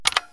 key-press-1.wav